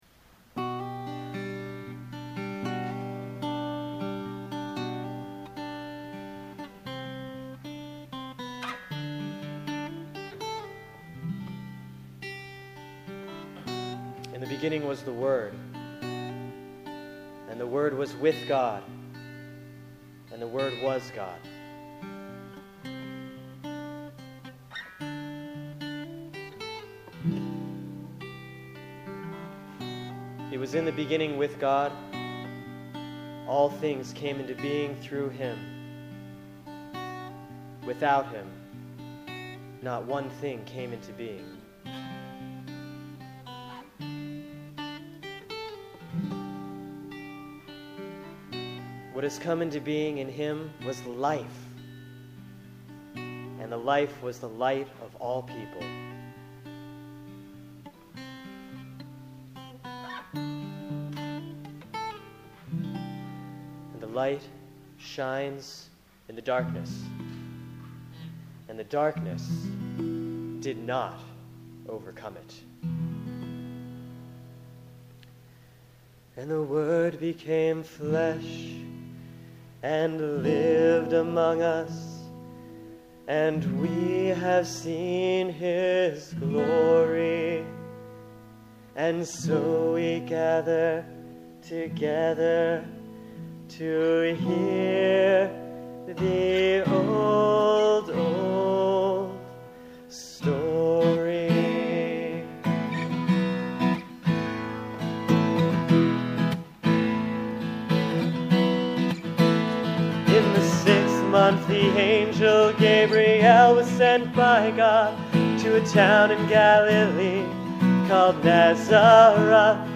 This recording is from this Christmas morning at St. Stephen’s. If you’d like to download an mp3 of the live recording (mistakes and all!), click here.
a-minstrels-christmas-song.mp3